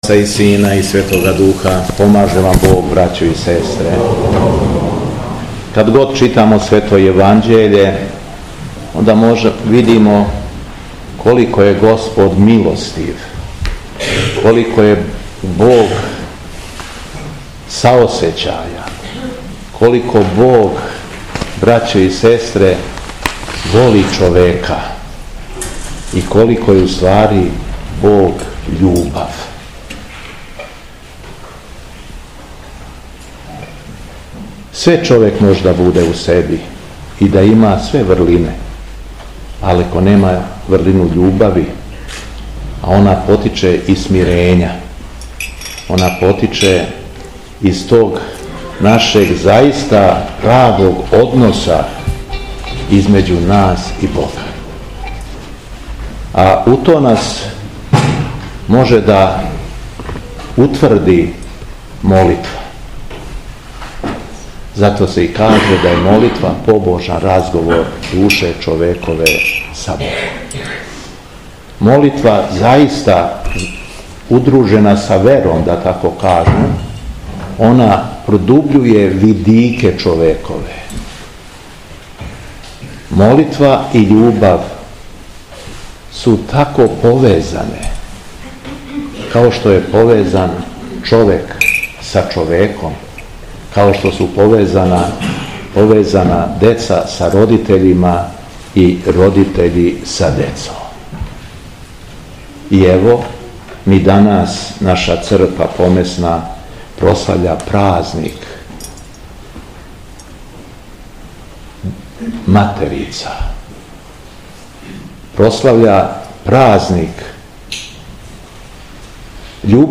У недељу 29. децембра 2024. године, Његово Високопресвештенство Митрополит шумадијски Г. Јован служио је Свету Литургију у Старој Цркви у Крагујевцу у...
Беседа Његовог Високопреосвештенства Митрополита шумадијског г. Јована